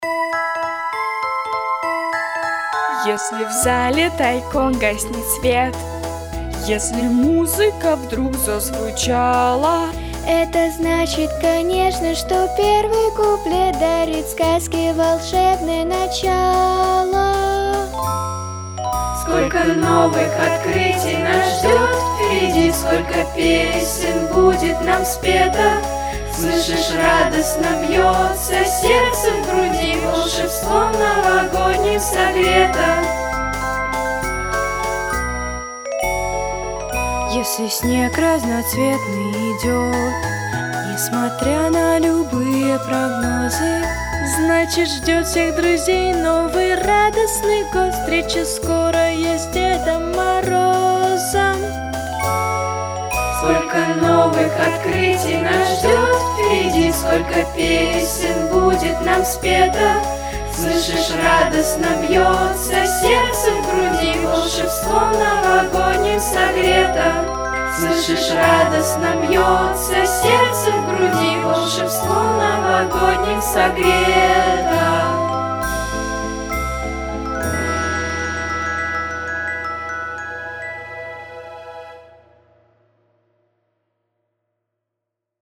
Записано в студии Easy Rider в декабре 2019 года